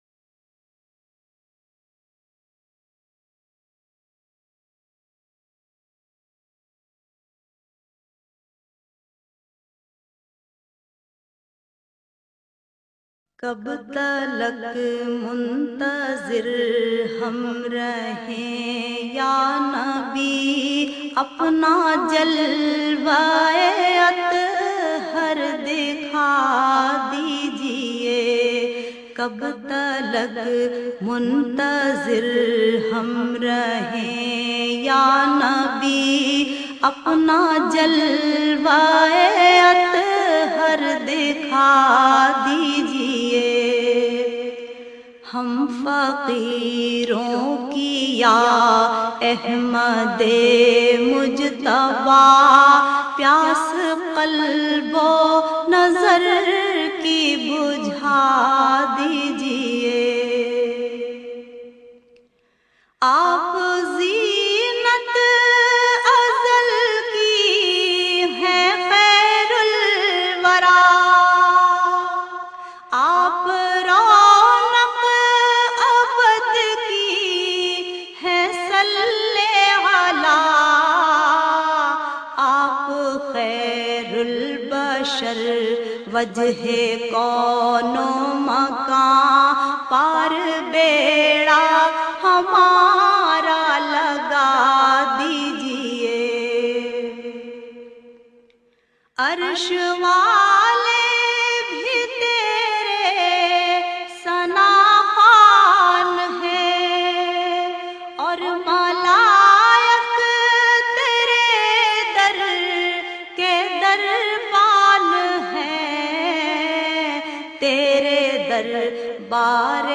She has her very own style of reciting Naats.